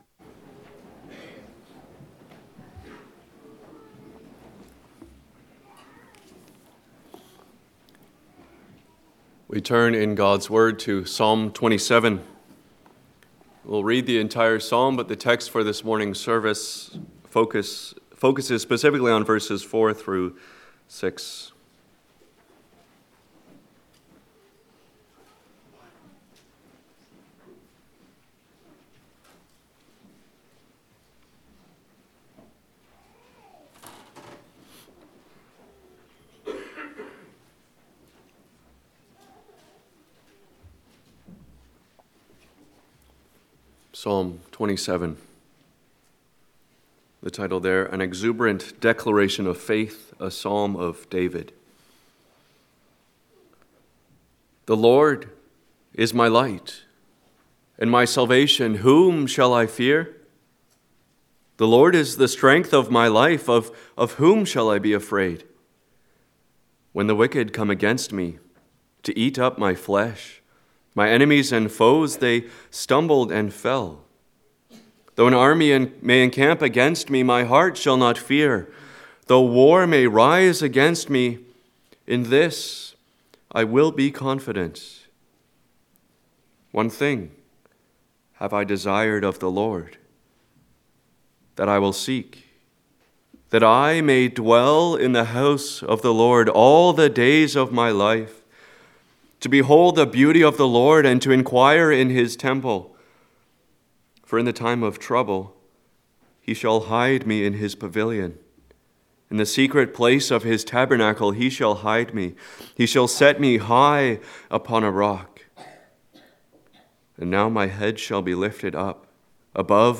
Passage: Psalm 27 Service Type: Sunday Morning « Jesus Said